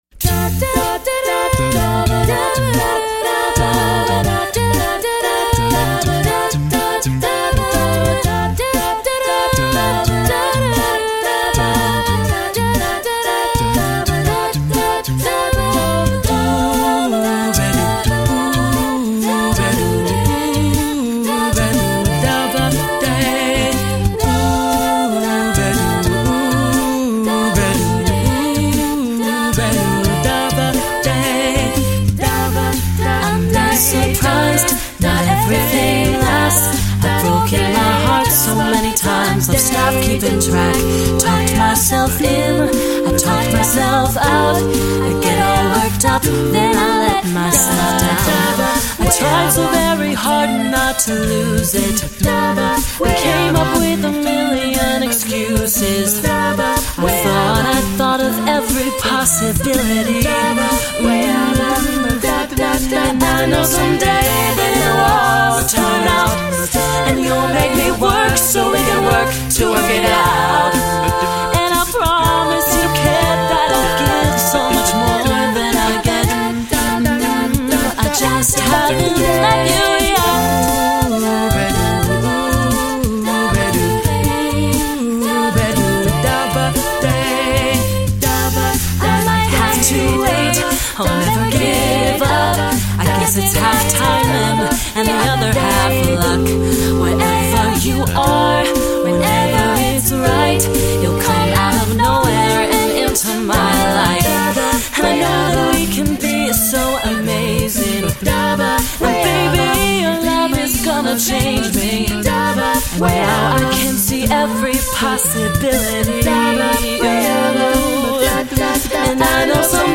choral
SATB, a cappella (SATB recording), sample